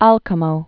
(älkə-mō)